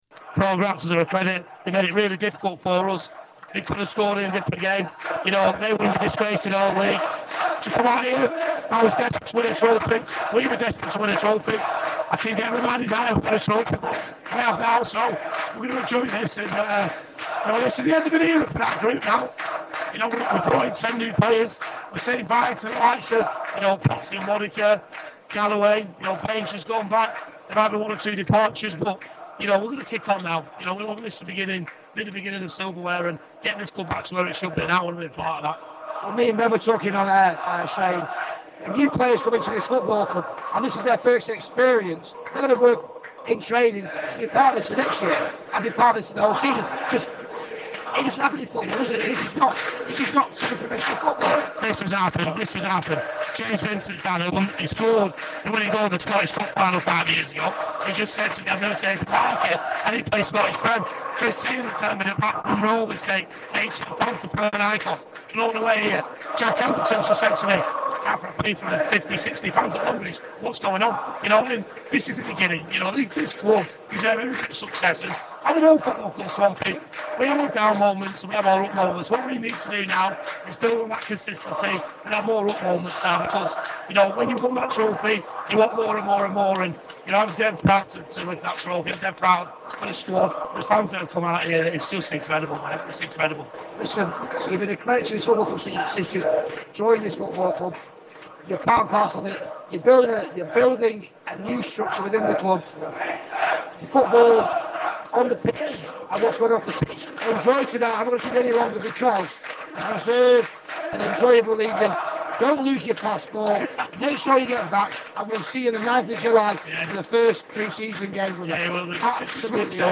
FCUM Radio / Post Match Interview